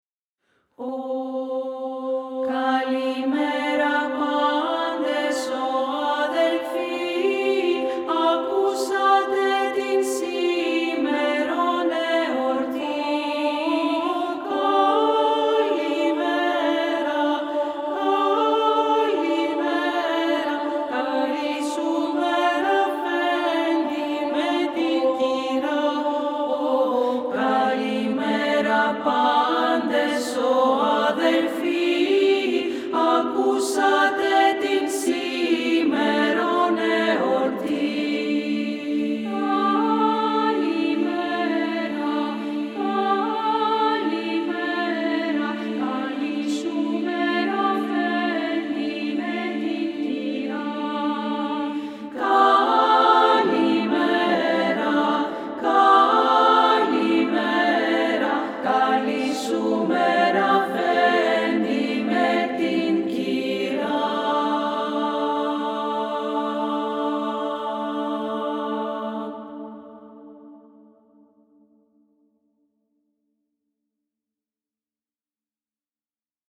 Theophany Carols from Patmos – for 3voices Female Choir